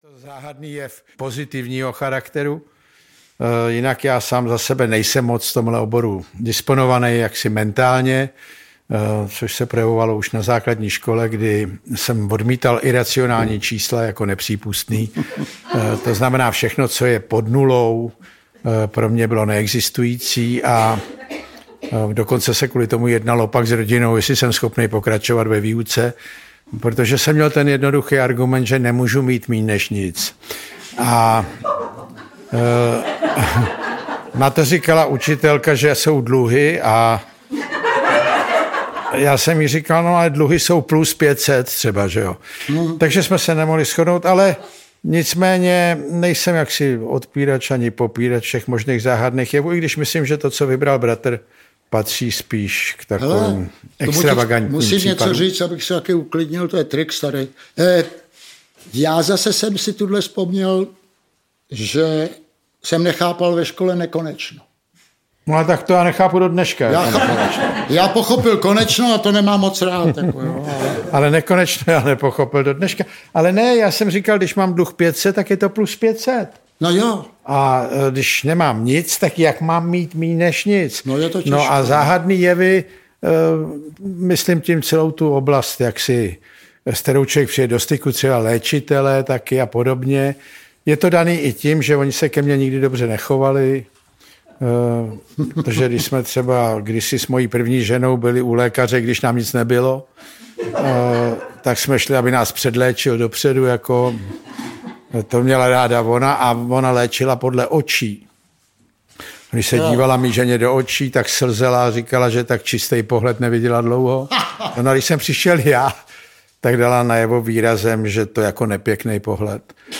Záhadné jevy audiokniha
Záznam představení z pražské Violy.
• InterpretJan Kraus, Ivan Kraus